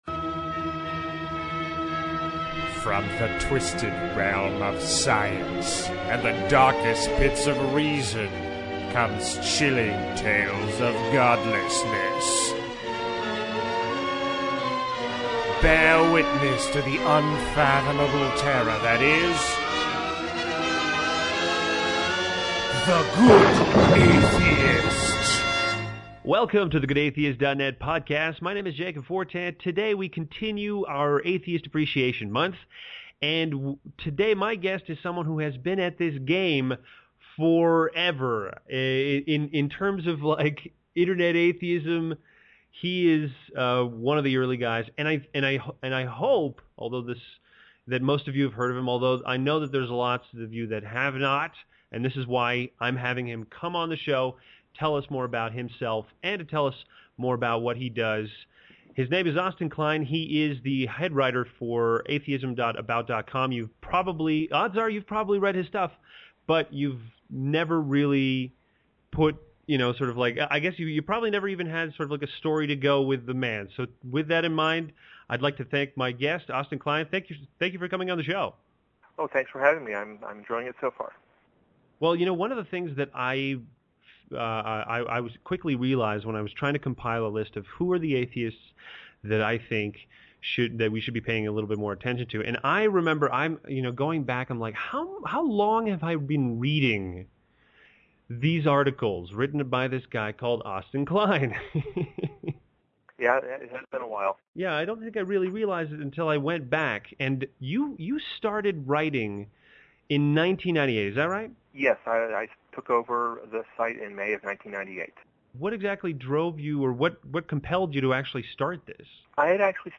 interviewing long time blogger